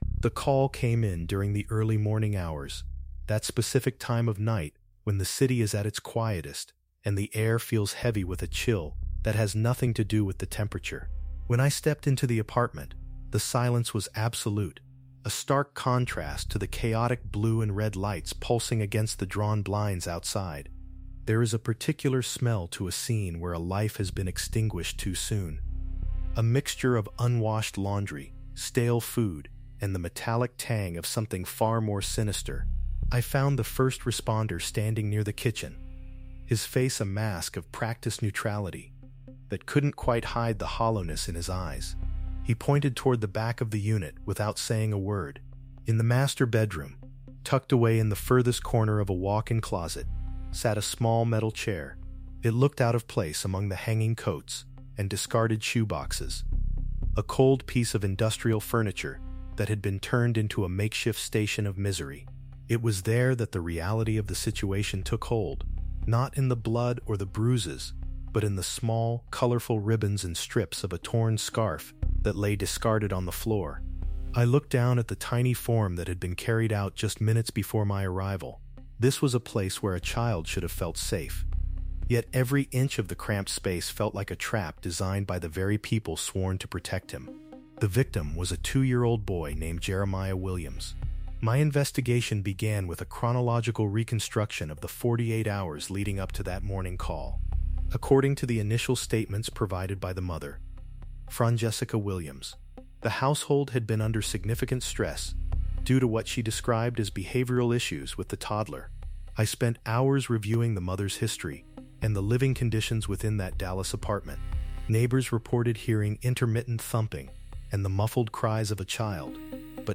In this true-crime documentary, we reconstruct the harrowing timeline leading up to the discovery in a cramped apartment closet, detailing the systemic caregiver failures and the extreme methods of restraint used against a defenseless child. Through a first-person detective narrative, we examine the forensic evidence, including the digital trail and the physical bindings that proved this was not a tragic accident, but a calculated and sustained period of physical abuse.